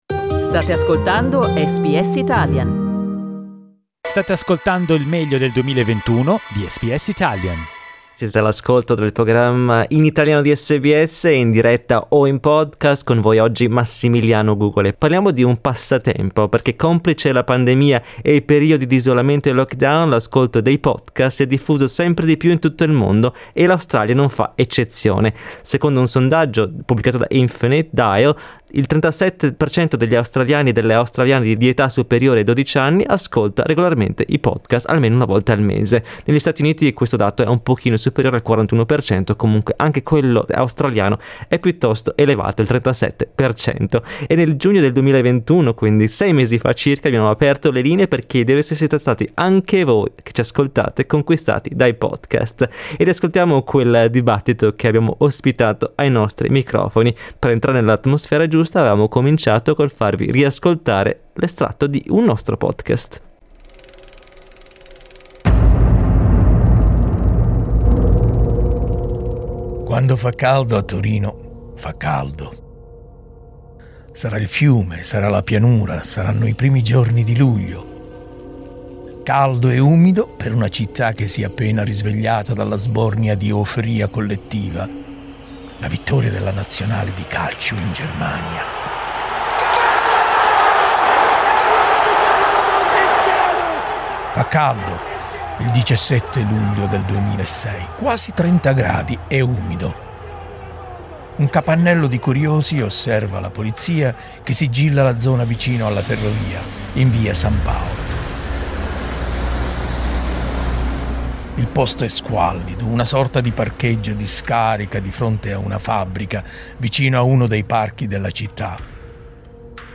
Dai nostri archivi, un dibattito dedicato all'ascolto di podcast, abitudine sempre più diffusa in Australia come in altre parti del mondo.